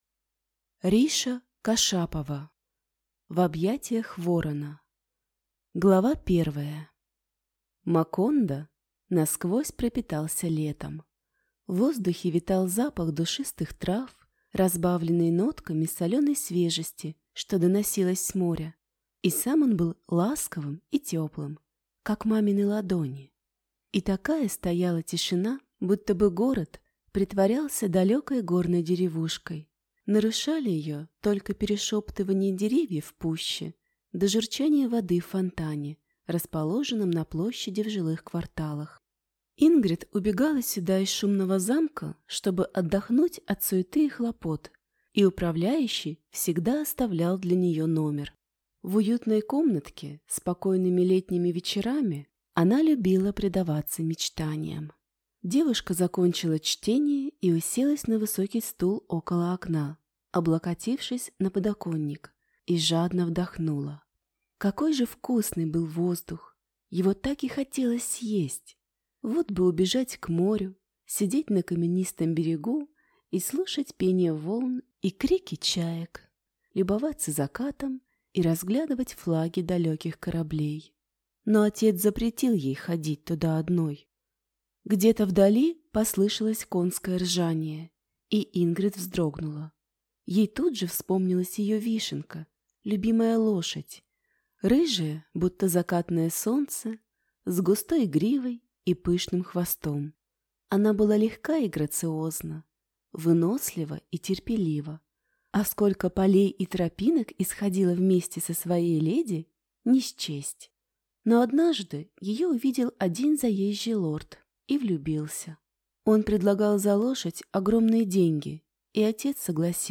Аудиокнига В объятиях ворона | Библиотека аудиокниг